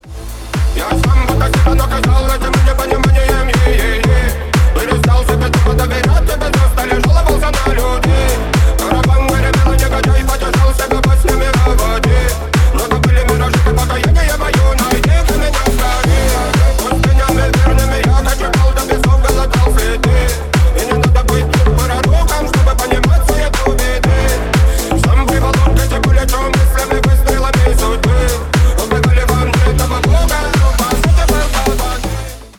Ремикс # Рэп и Хип Хоп
клубные